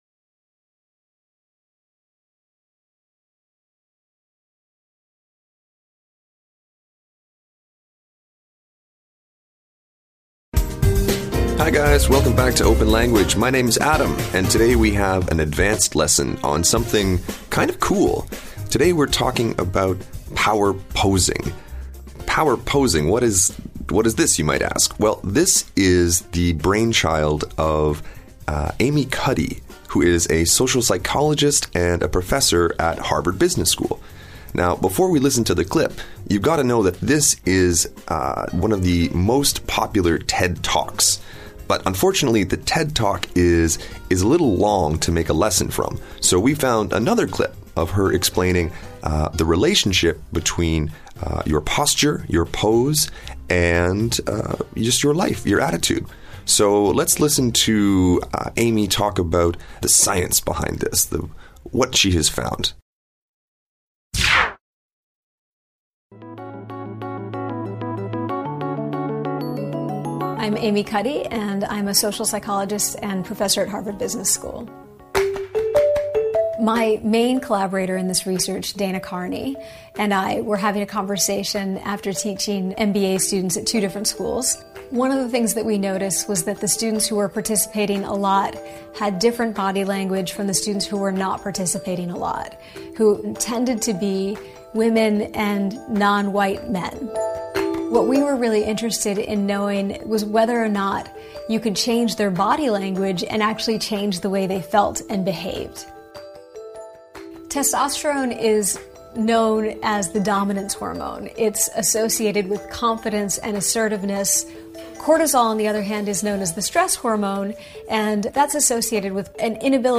试听课程精彩片段 ↓% Q- p2 N0 \: m2 _5 v$ d' m9 f